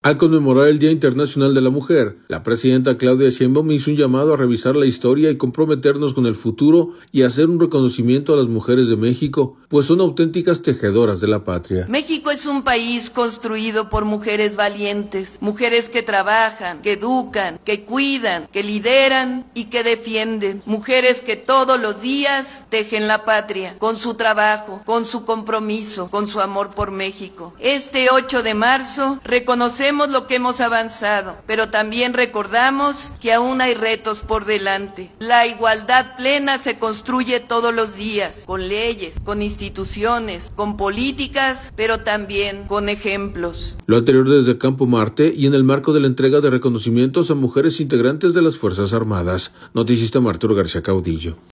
Lo anterior desde el Campo Marte y en el marco de la entrega de reconocimientos a mujeres integrantes de las Fuerzas Armadas.